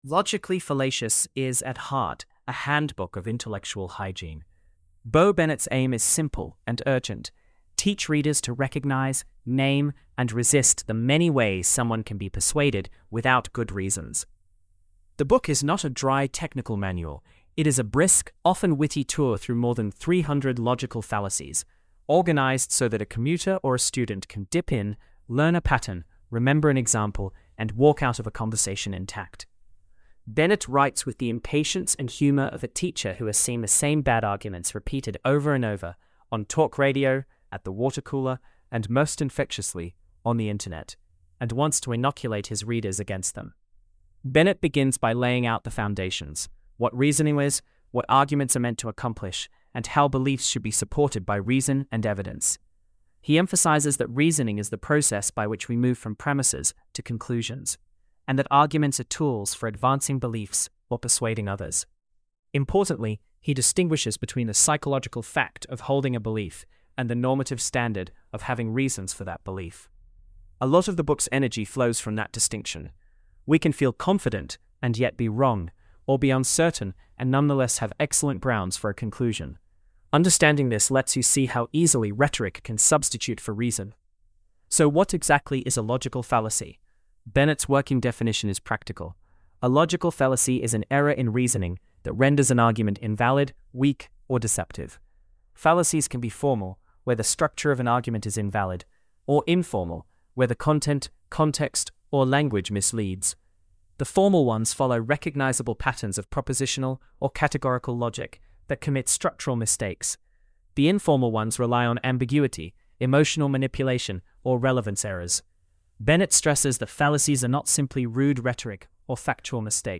Read or listen to the AI-generated summary of